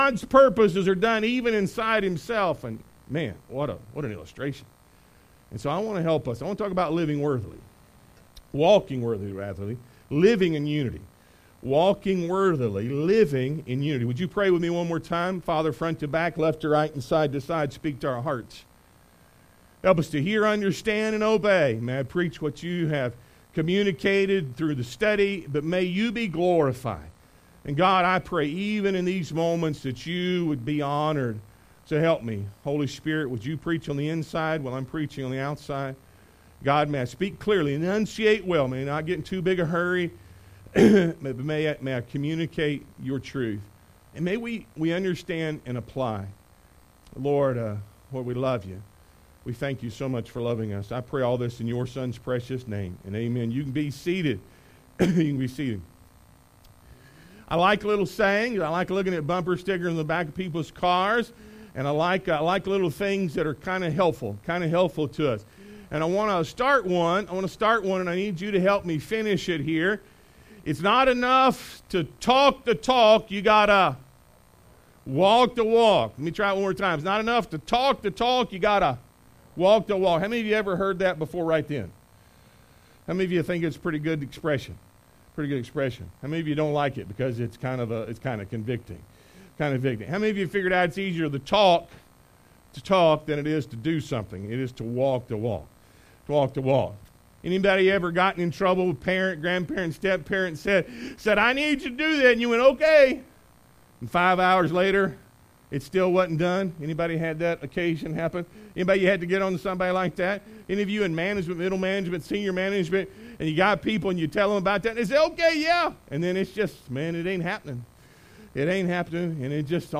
Ephesians 4:1-6 Service Type: Sunday AM Walk Worthily